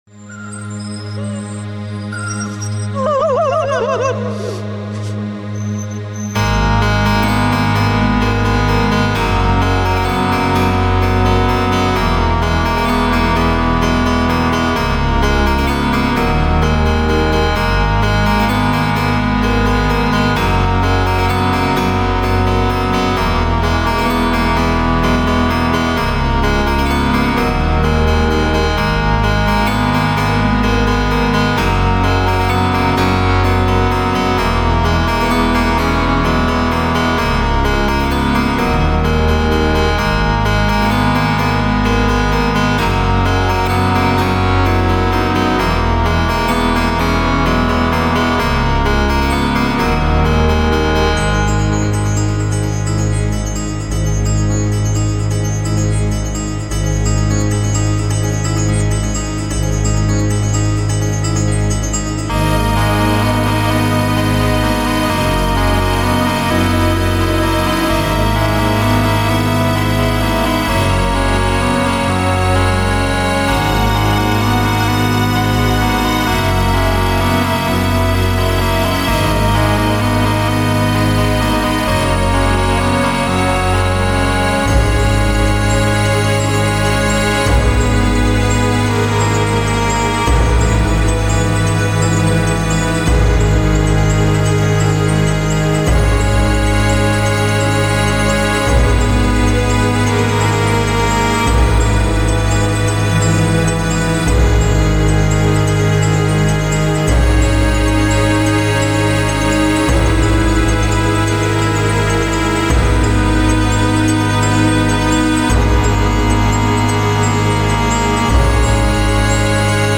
BANDA SONORA OFICIAL DE LA SERIE
Dark ambient / Cinematic sorrow / Ritual tones